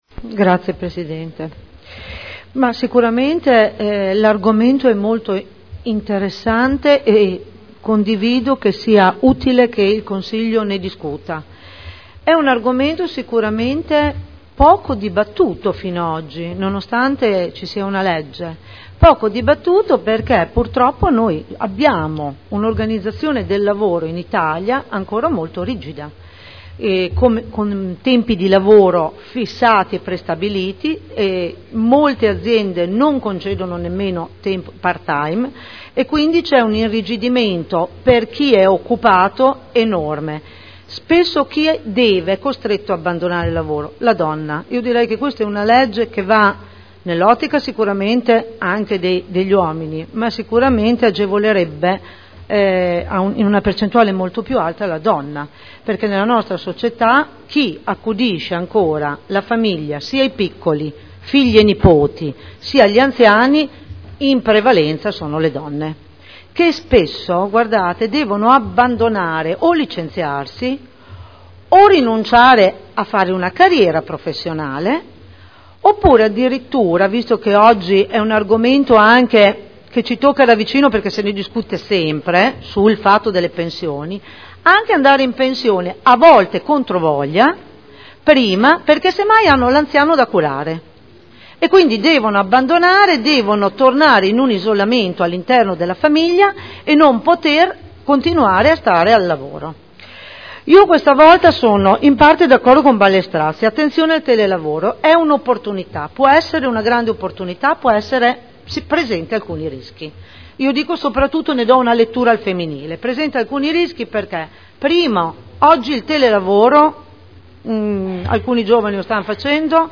Cinzia Cornia — Sito Audio Consiglio Comunale